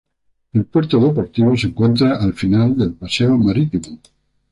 ma‧rí‧ti‧mo